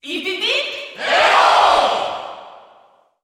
Category: Hero (SSBU) Category: Crowd cheers (SSBU) You cannot overwrite this file.
Hero_Cheer_French_SSBU.ogg.mp3